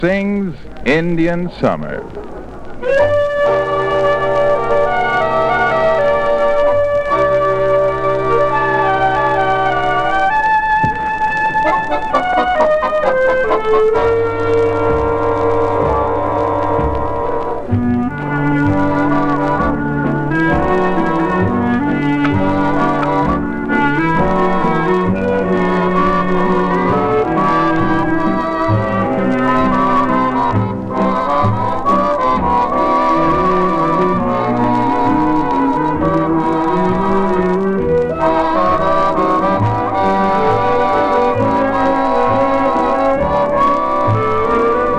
Jazz, Swing　USA　12inchレコード　33rpm　Mono